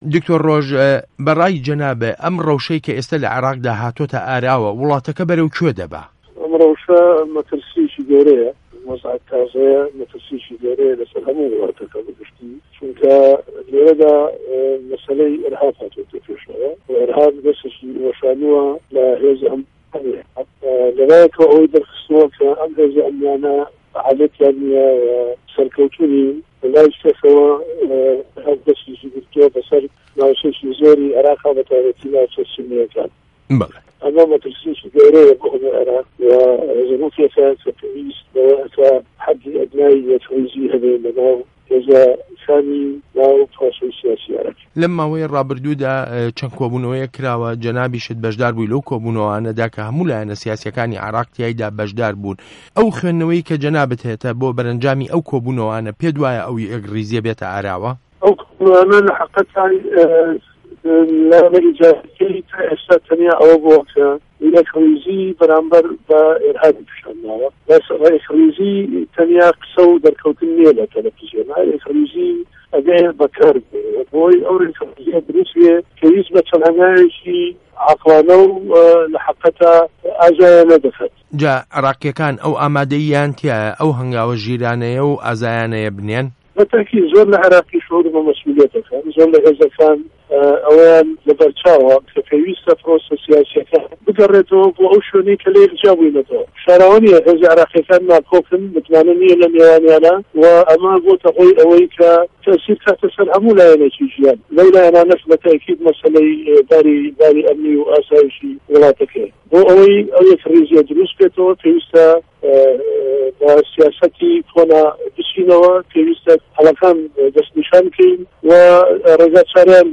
وتووێژ له‌گه‌ڵ دکتۆر ڕۆژ شاوێس